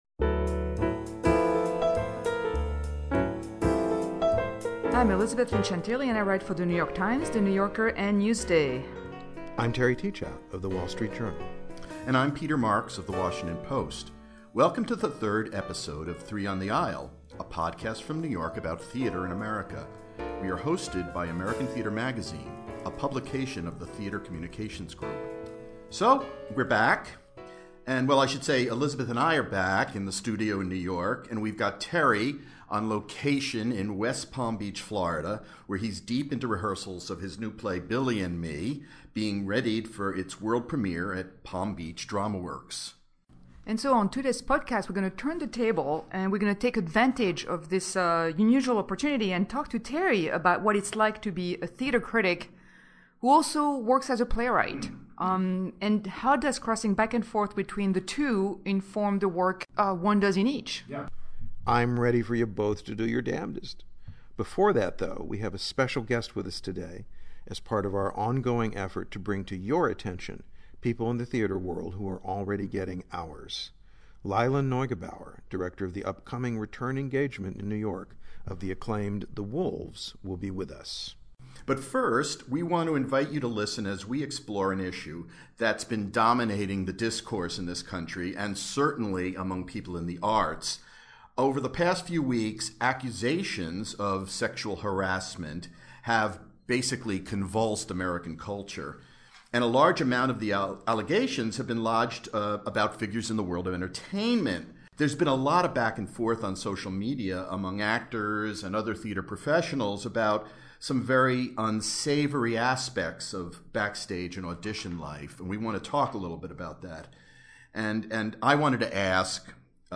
This week Teachout calls in from West Palm Beach, Fla., where he is working on his new play, Billy and Me, about the friendship between Tennessee Williams and William Inge, at Palm Beach Dramaworks. Teachout, who also wrote (and has directed a production of) Satchmo at the Waldorf, discusses what it’s like to be on the other side of the curtain: How does his playwriting inform his criticism, and vice versa?